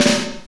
Index of /90_sSampleCDs/Northstar - Drumscapes Roland/SNR_Snares 1/SNR_R&B Sn Buzzx